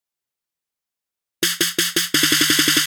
This Ringtone Is Just Dirty With The Snare.